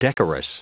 Prononciation du mot decorous en anglais (fichier audio)
Prononciation du mot : decorous